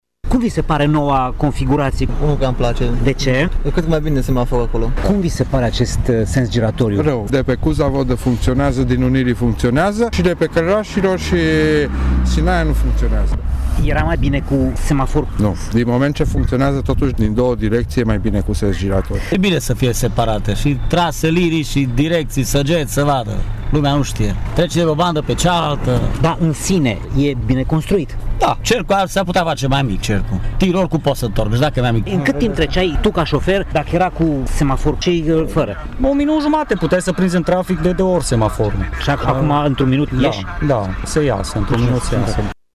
Nu toți șoferii sunt mulțumiți de modul în care este proiectat acest sens giratoriu, însă cei mai mulți sunt de acord că e mult mai bine decât atunci când zona era semaforizată: